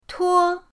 tuo1.mp3